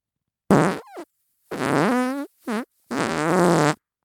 FART SOUND 43
Category 🤣 Funny